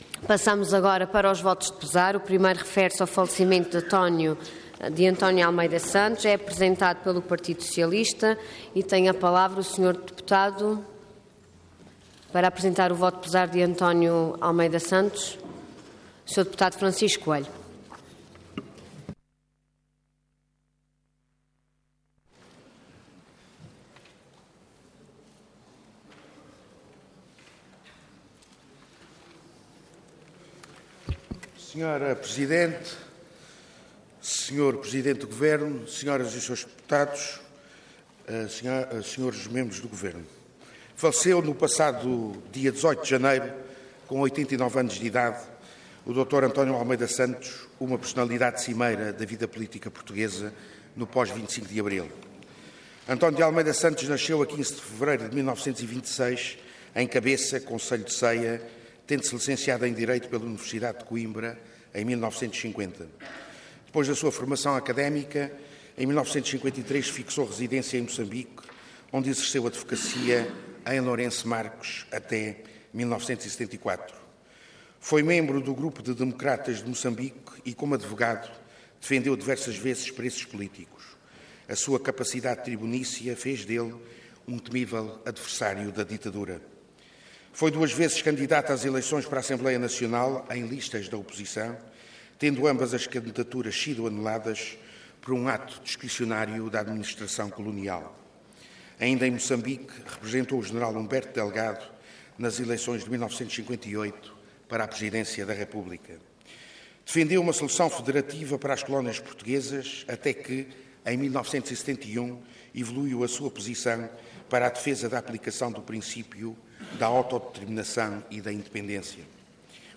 Detalhe de vídeo 16 de março de 2016 Download áudio Download vídeo Processo X Legislatura António de Almeida Santos Intervenção Voto de Pesar Orador Francisco Coelho Cargo Deputado Entidade PS